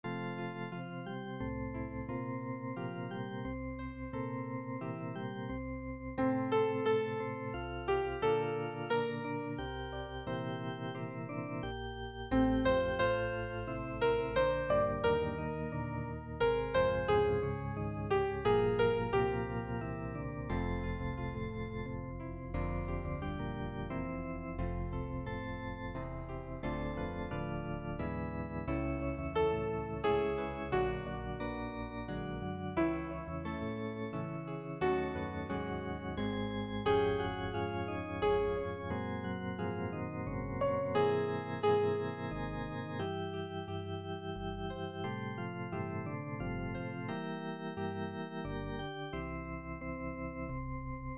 Chanté: